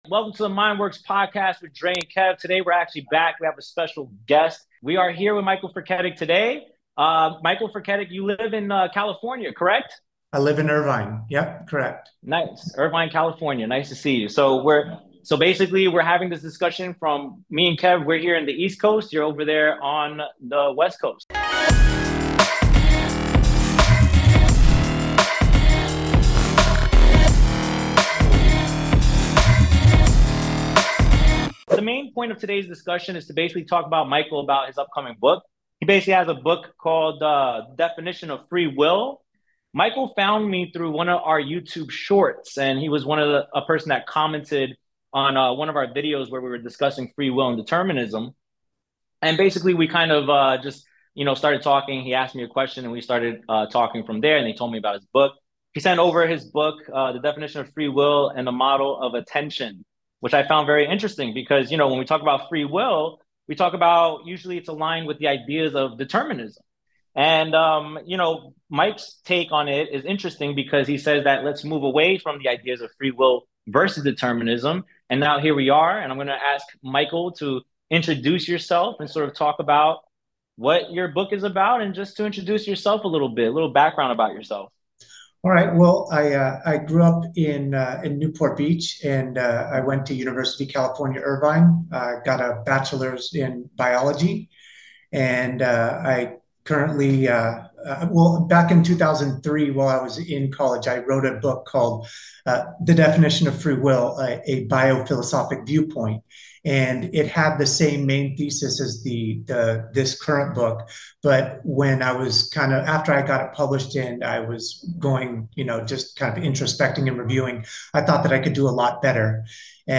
The Mind Work Podcast is the podcast that will help enhance your knowledge and perception in mental health, psychology, and philosophy. Two Latino mental health professionals from NY discuss the field of mental health from their perspective.